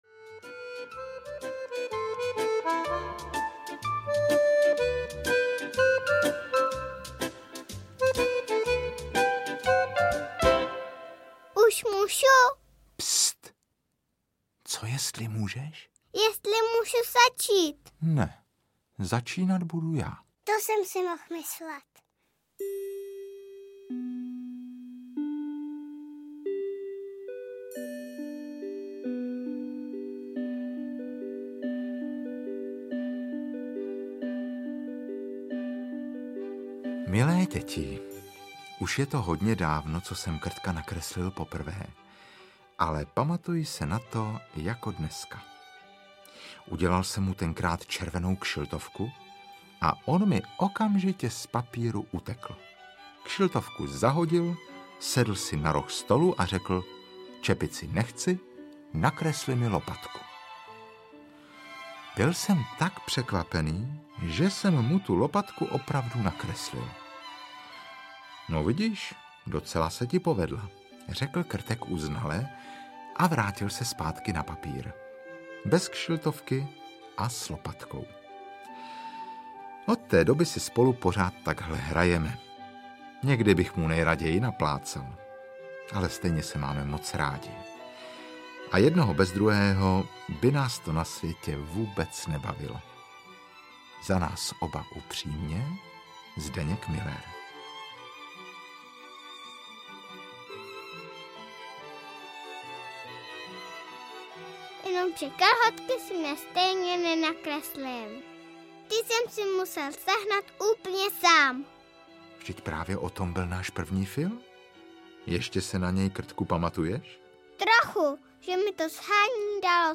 Krtkova dobrodružství 1 audiokniha
Ukázka z knihy
Vypravěčem historek z Krtečkova života se stal Marek Eben a hlavní hrdina promlouvá roztomilým hlasem čtyřletého dítěte.
S použitím původníhudby tak vznikla dokonalá akustická obdoba kresleného příběhu.